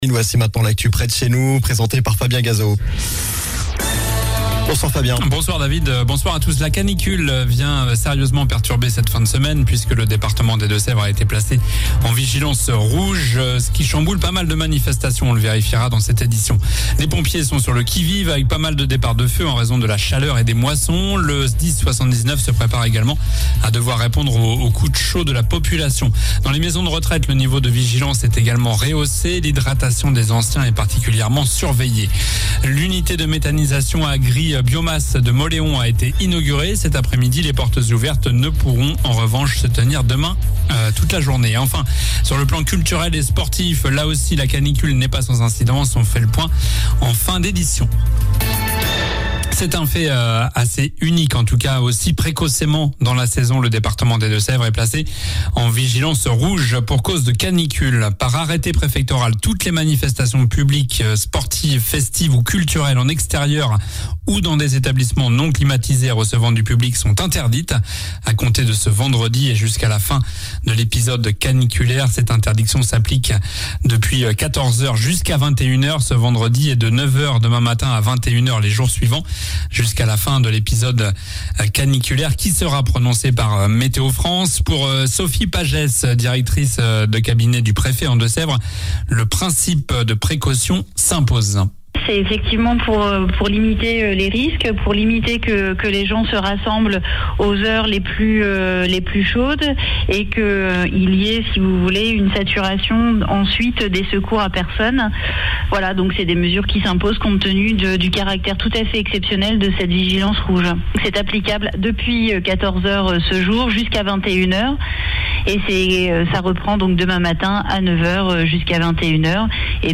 Journal du vendredi 17 juin (soir)